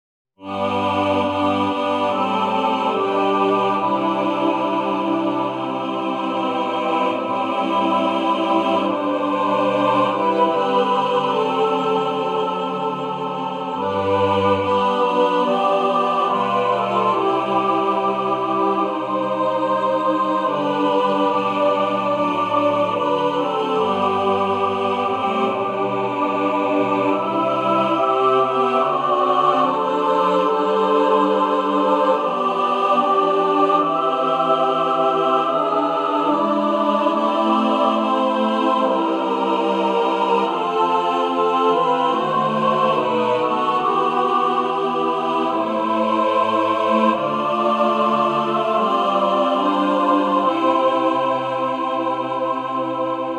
A beautiful, sacred hymn